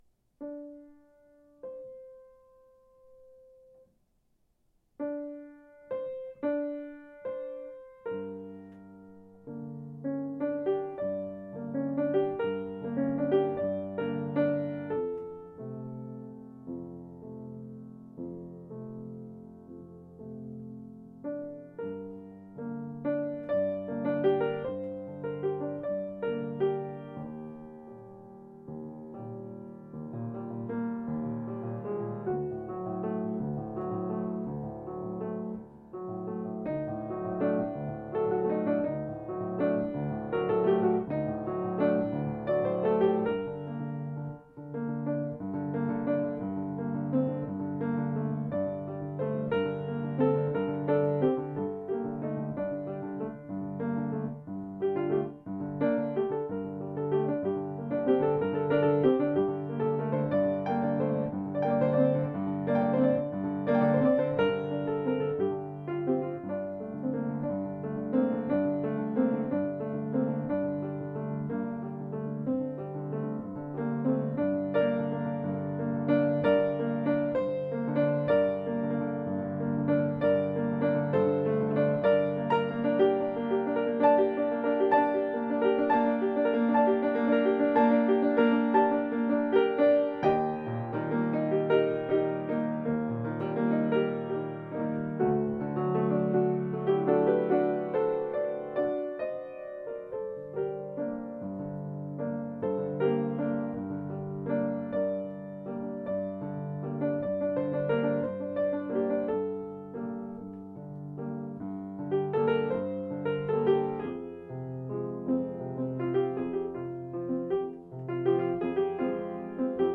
Piano | Play It Again Swig | Page 4
daily improv 1-20-13
I started with a strong “I want” feel (I’m upset about a scheduling conflict for the summer–it’s a first world problem) and then played with moving to different keys, which I need to get better at. I tried to incorporate my mistakes, especially my hesitations, into the fabric of what I was doing.